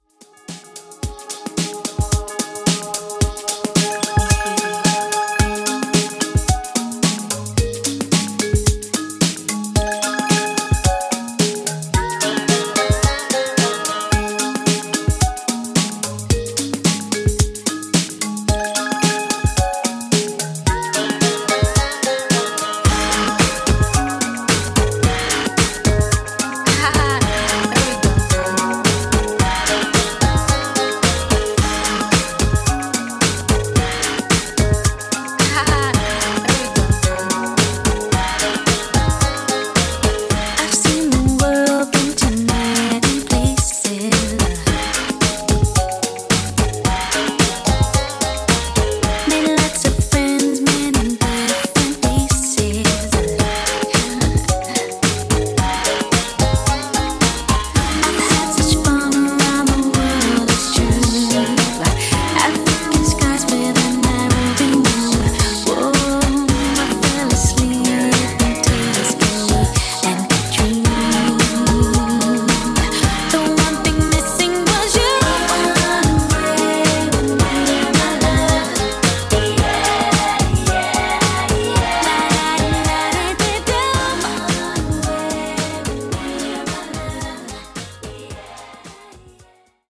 90s Club Redrum)Date Added